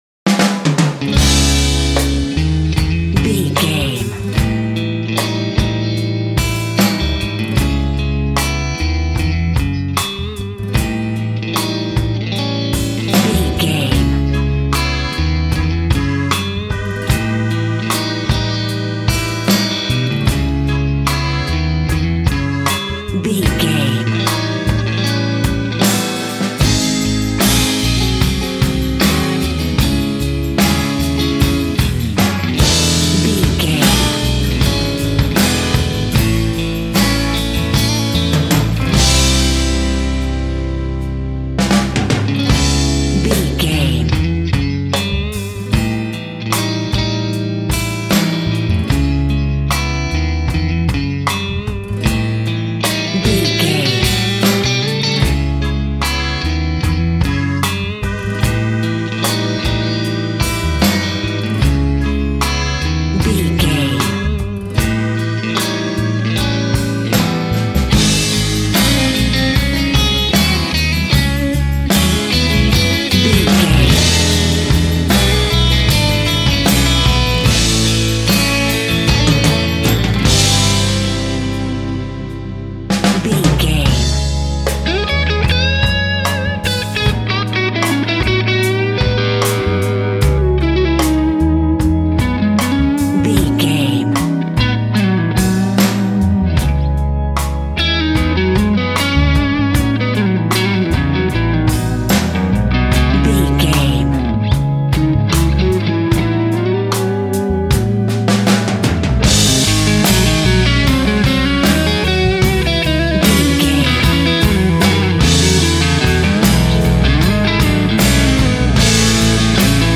sultry, mysterious, sexy, alluring, dramatic, haunting
Aeolian/Minor
C#
Slow
accordion
electric guitar
drums
acoustic guitar